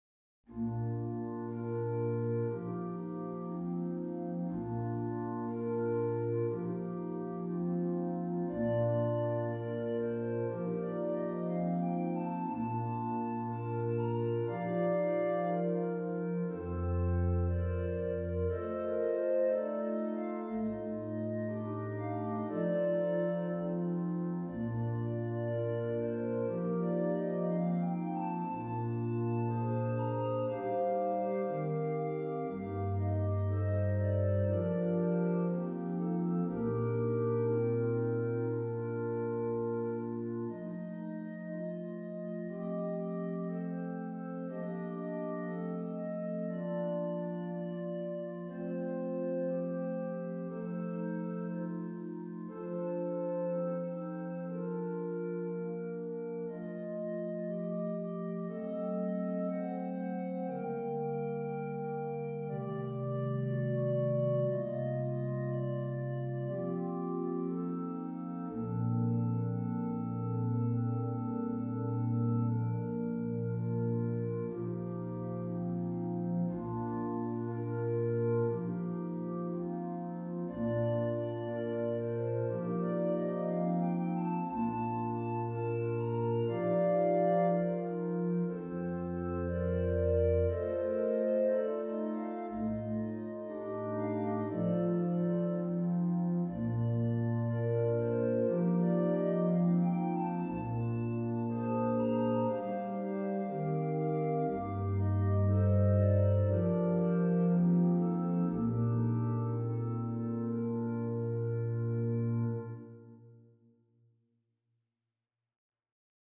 'Little Aria' for organ